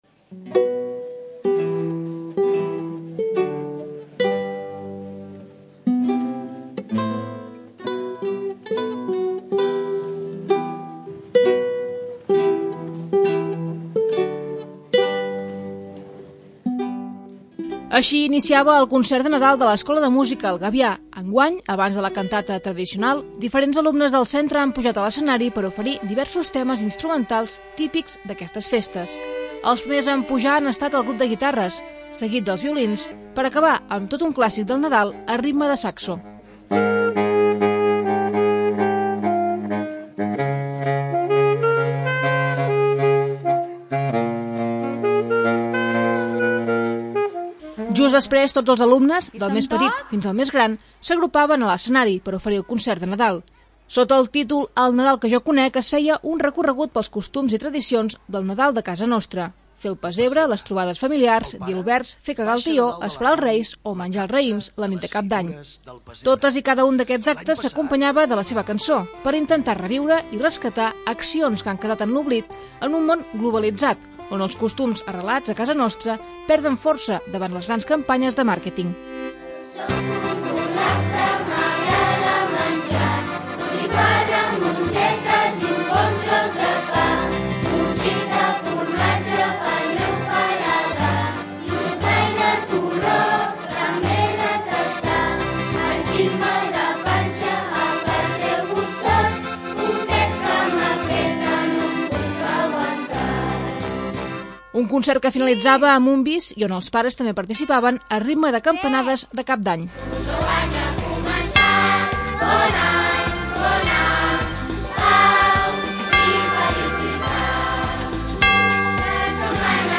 Sota el títol 'El Nadal que jo conec', l'Escola de Música El Gavià ha celebrat el seu tradicional concert de Nadal a la Sala Polivalent. Enguany amb una primera part de concert musical i la segona amb la cantata. Tots els alumnes de l'escola han participat en el concert, i on els pares també hi han tingut un petit paper al final de totes les actuacions.
Enguany abans de la cantata tradicional, diferents alumnes del centre han pujat a l'escenari per oferir diversos temes instrumentals típics d'aquestes festes. Els primers a pujar han estat el grup de guitarres, seguit dels violins, per acabar amb un clàssic a ritme de saxo com és All I Want for Christmas Is You.